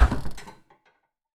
sounds / doors / metal